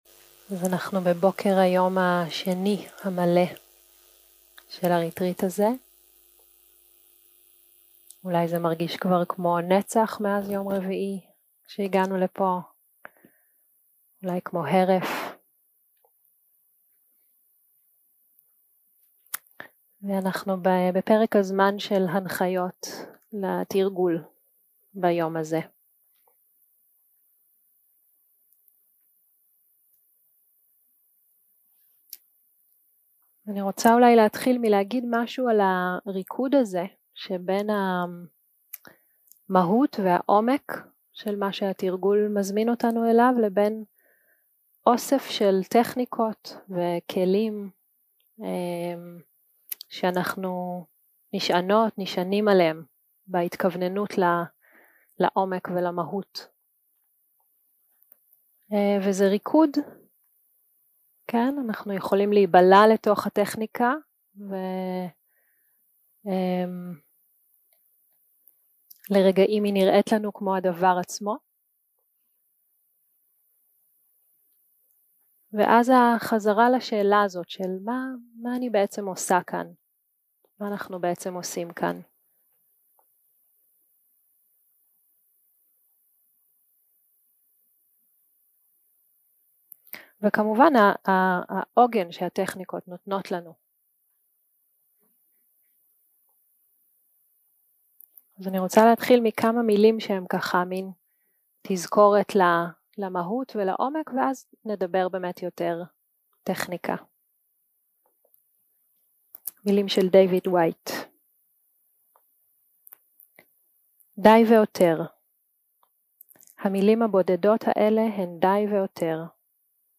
יום 3 - הקלטה 5 - בוקר - הנחיות למדיטציה - גוון רגשי וחמשת האורחים 0:00 0:00 סוג ההקלטה: Dharma type: Guided meditation שפת ההקלטה: Dharma talk language: Hebrew